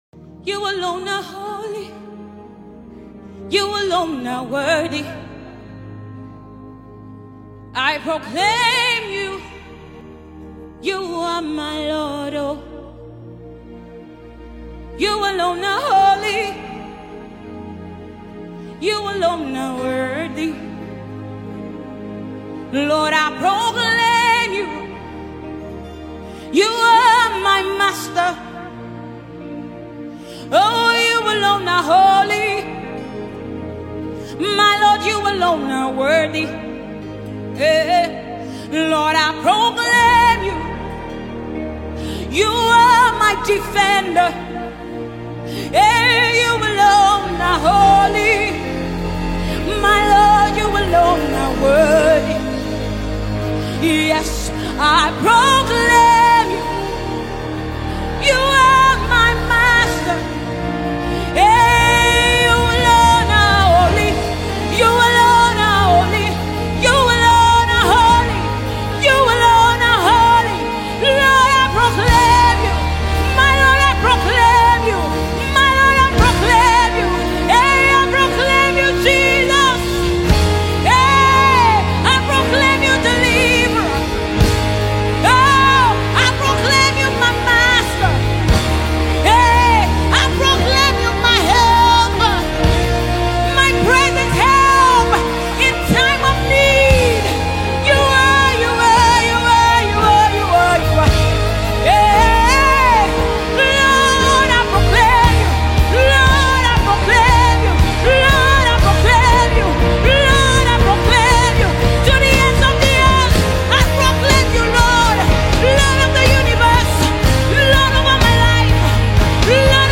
Mp3 Gospel Songs
Famous Nigerian gospel singer, pastor, and songsmith